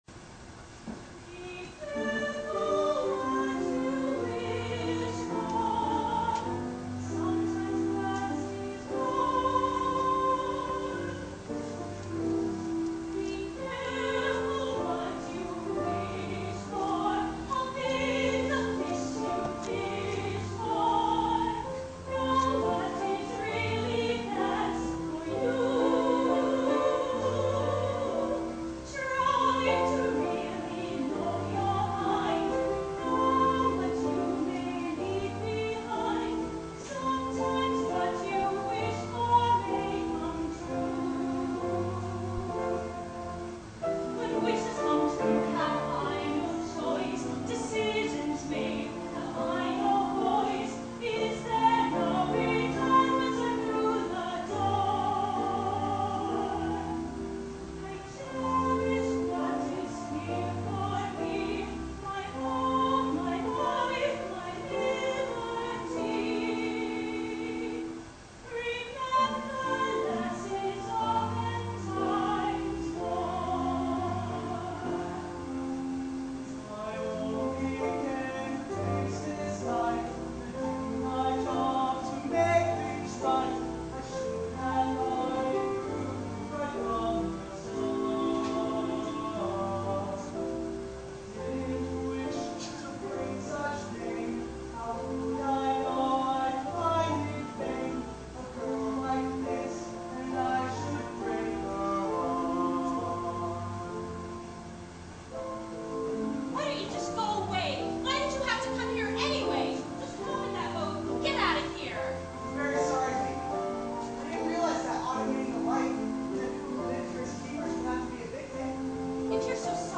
so it is not of very high quality.  The feeling is there, though, and the excitement of the night comes through.
PREMIER PERFORMANCE, WALDO THEATER, JULY, 2008